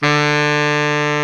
SAX B.SAX 16.wav